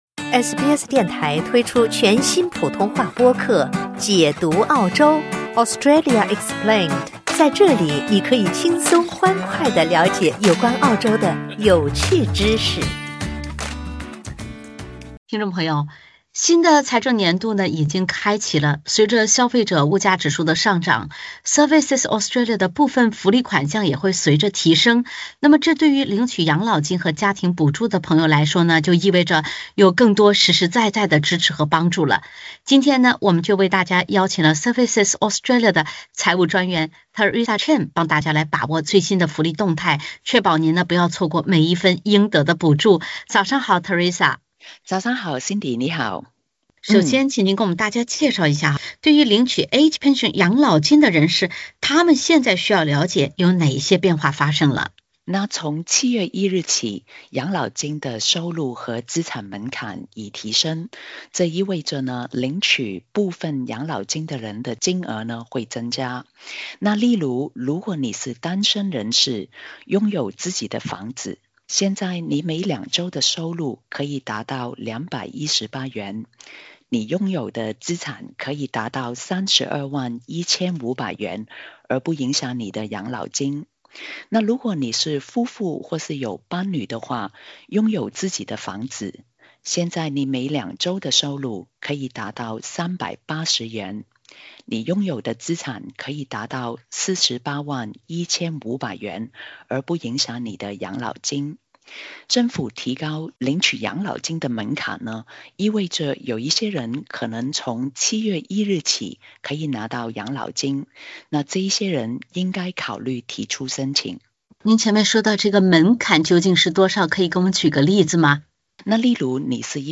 Services Australia工作人员接受本台采访，详细解读这些调整。